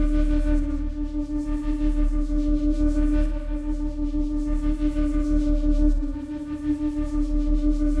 Index of /musicradar/dystopian-drone-samples/Tempo Loops/90bpm
DD_TempoDroneB_90-D.wav